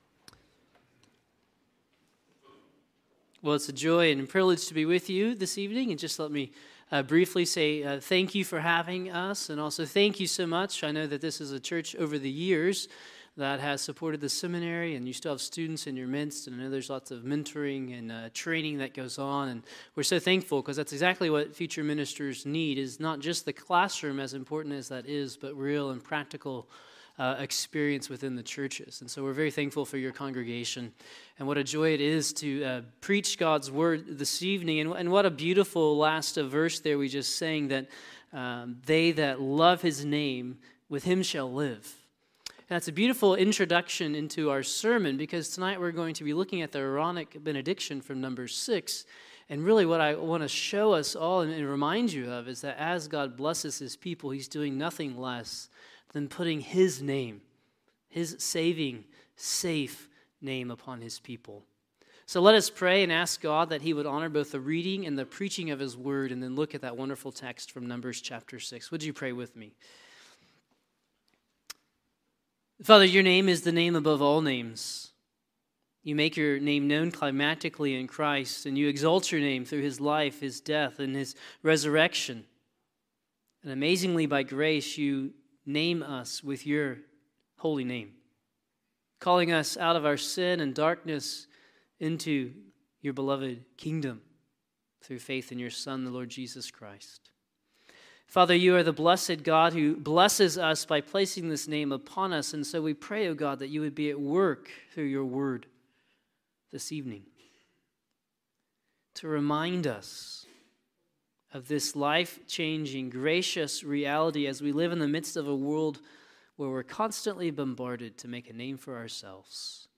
Numbers — Sermons — Christ United Reformed Church